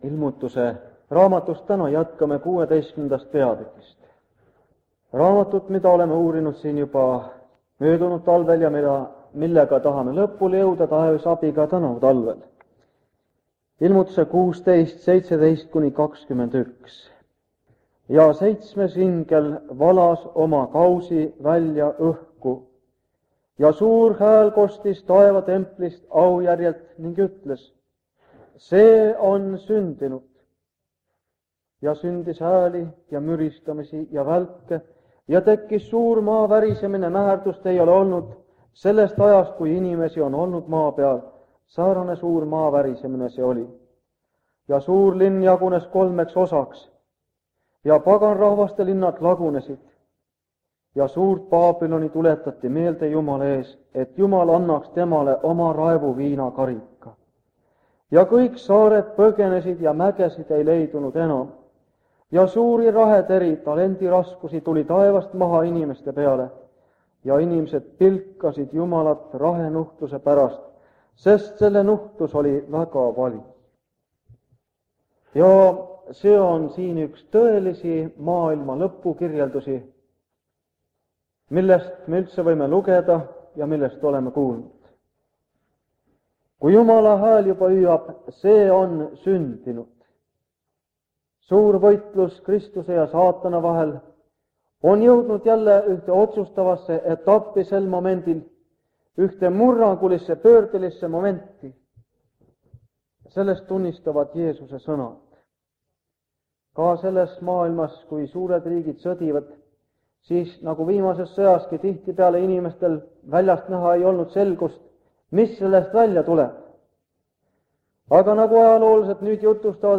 Jutlused
Ilmutuse raamatu seeriakoosolekud Kingissepa linna adventkoguduses